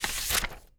TURN PAGE3-S.WAV